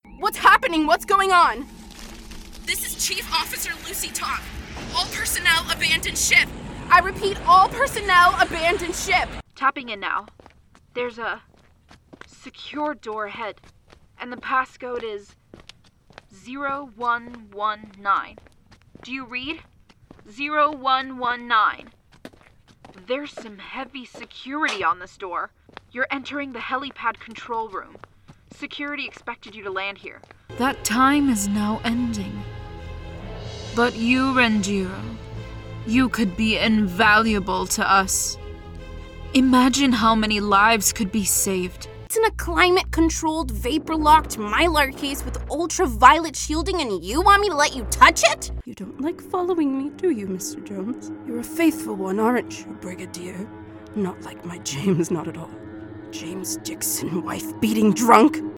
Jeux vidéo
Ma voix combine naturellement chaleur, clarté et énergie dynamique, ce qui en fait un choix parfait pour les projets qui nécessitent un son relatable et professionnel.